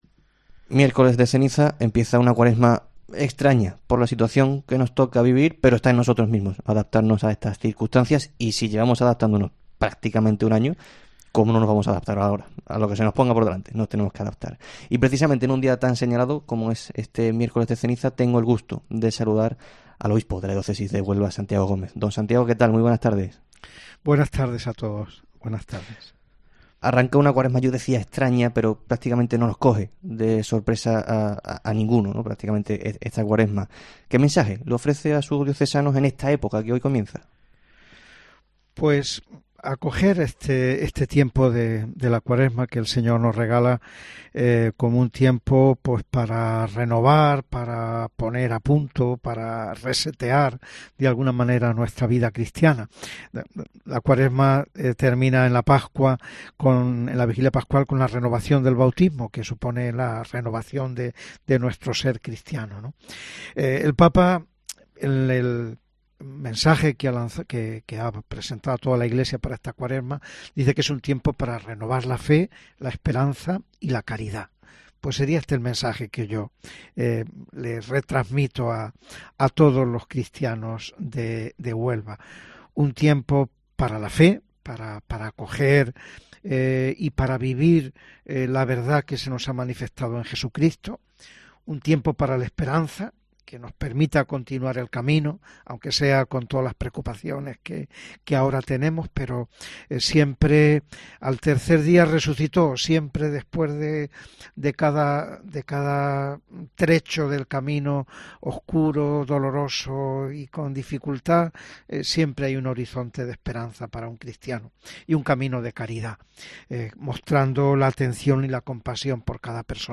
AUDIO: Con motivo del Miércoles de Ceniza, en el Herrera en COPE Huelva de este 17 de febrero nos ha atendido Santiago Gómez, obispo de la Diócesis...